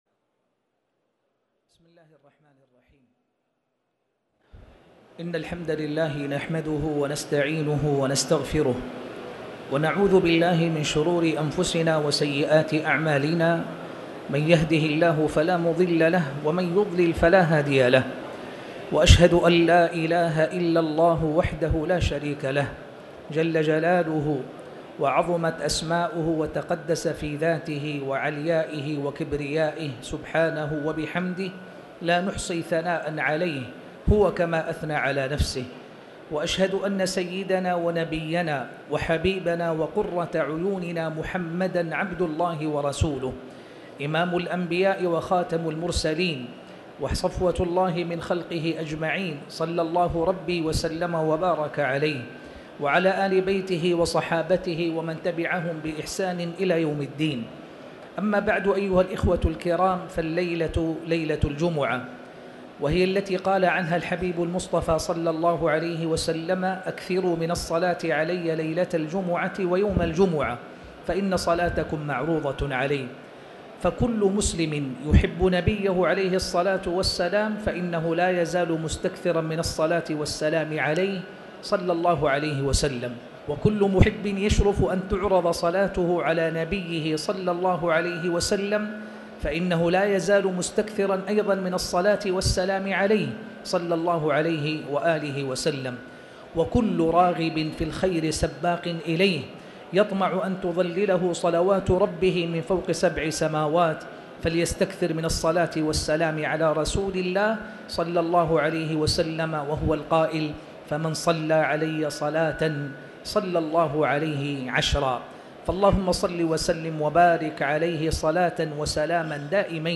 تاريخ النشر ٢٧ جمادى الآخرة ١٤٣٩ هـ المكان: المسجد الحرام الشيخ